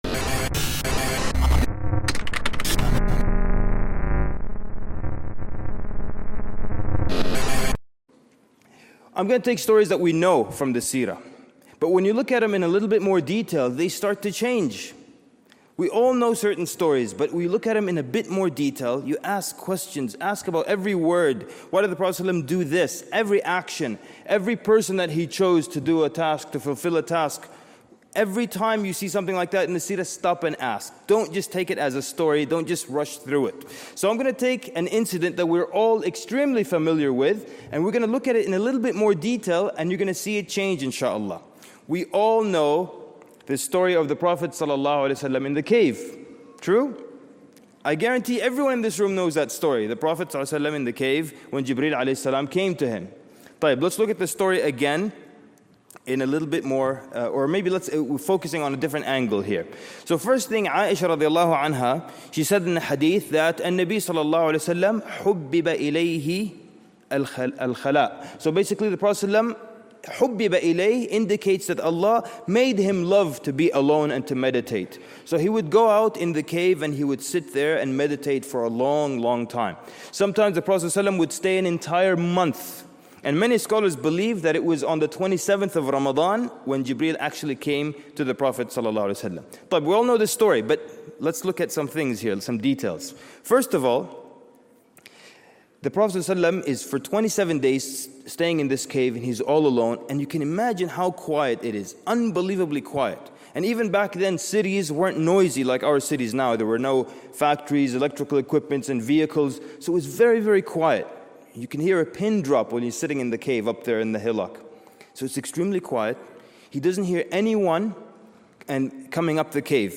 But when you slow down and examine every detail of the Seerah, familiar stories transform into extraordinary lessons in character, planning, and prophetic wisdom. This lecture encourages Muslims to revisit the Seerah with fresh eyes and a questioning mind.